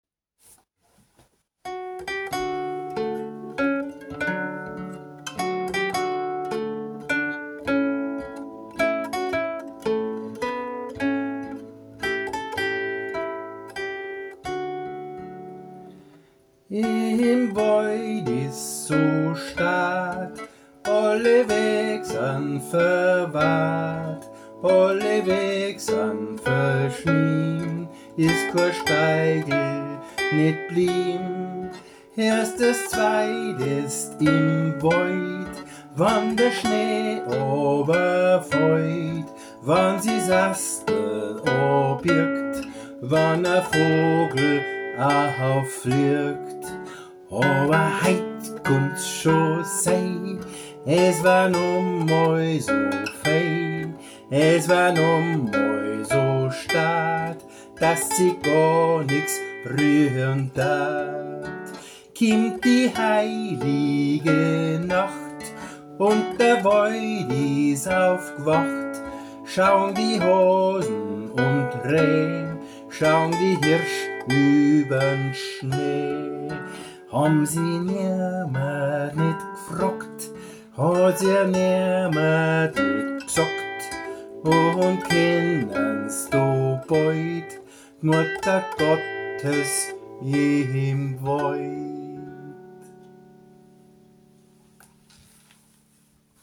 An der Zither
Erster Gesang